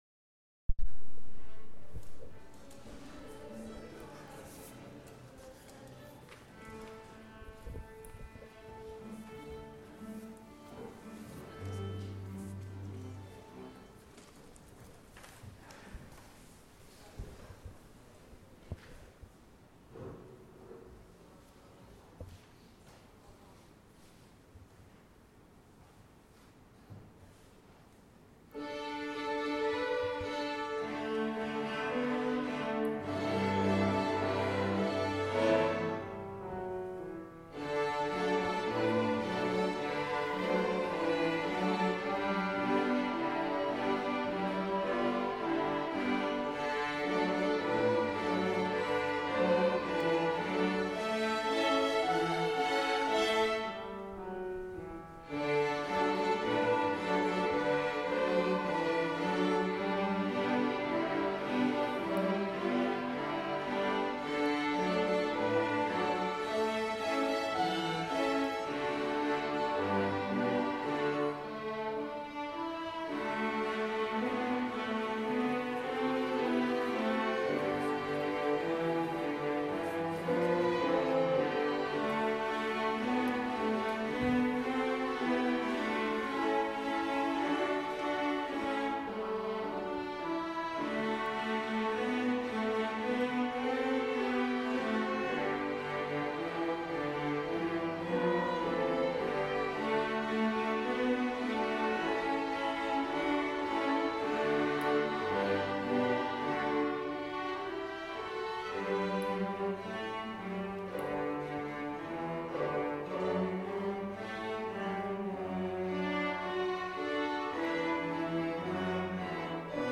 String Ensemble
Strings Concert Feb 2015